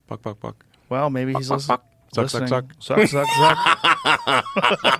Elon Musk taunting Mark Zuckerberg